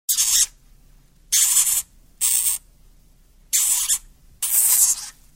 Здесь собраны самые забавные и жизненные аудиозаписи: от веселого похрустывания корма до довольного попискивания.
Звук пронзительного свиста хомяка